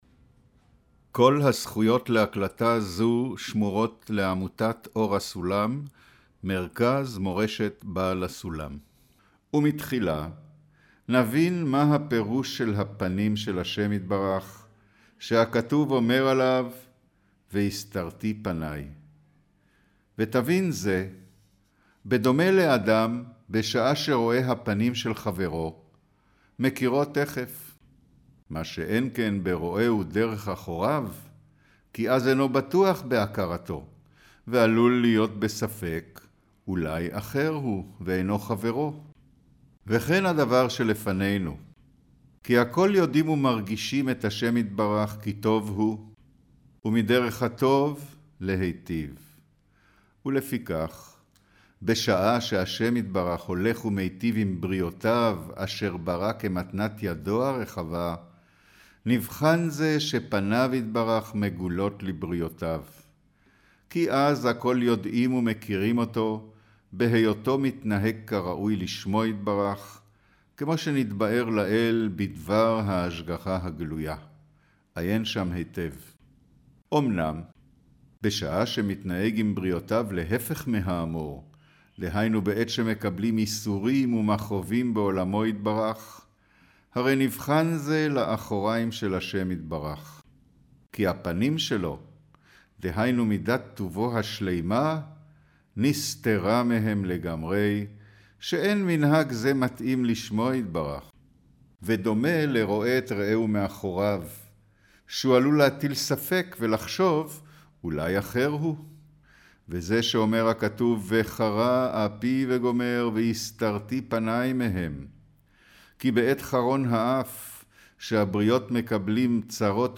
אודיו - קריינות הקדמה לתלמוד עשר הספירות אות מז' - פה'